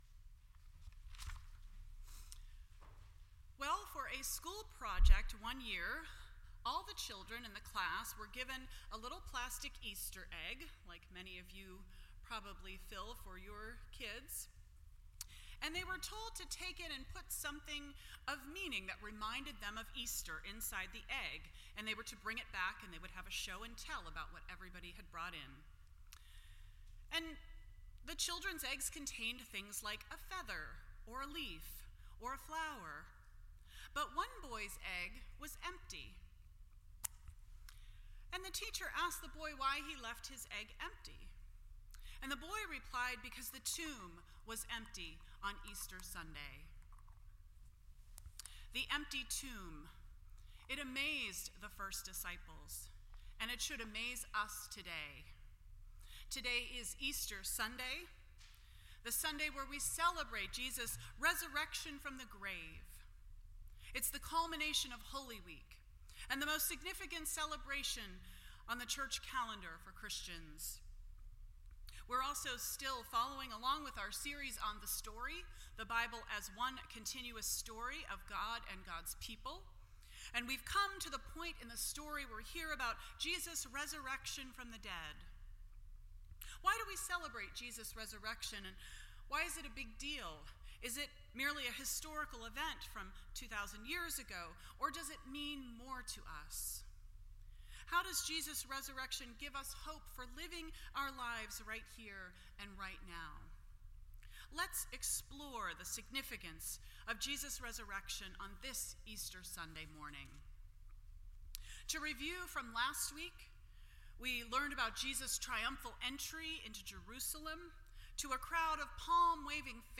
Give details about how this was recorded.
The Story Service Type: Easter Sunday %todo_render% Share This Story